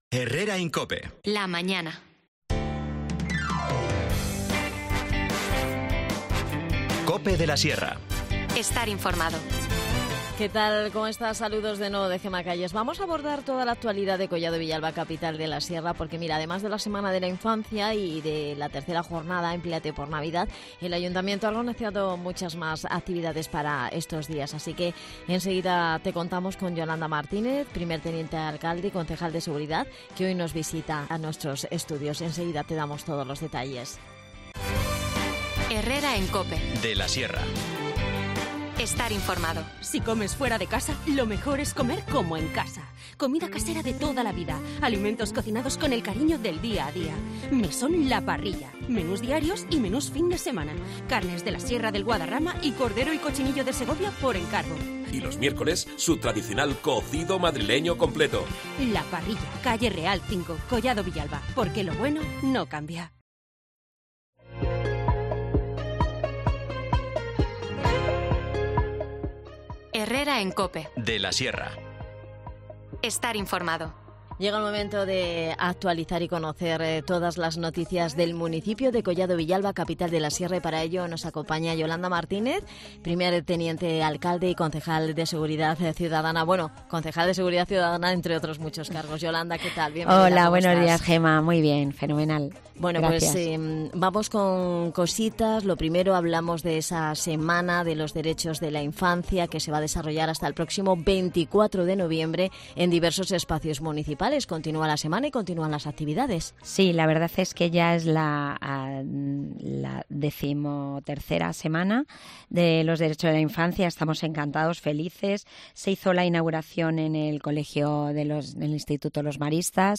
12:50 | Magazín | Herrera en COPE de la Sierra, 23 de noviembre de 2023
Yolanda Martínez, Primer Teniente Alcalde y concejal de Seguridad Cuidadana, nos visita para repasar toda la actualidad de Collado Villalba, Capital de la Sierra, que pasa por hablar de las actividades que han programado hasta el este viernes 24 de noviembre dentro de la XIII Semana de los Derechos de la Infancia.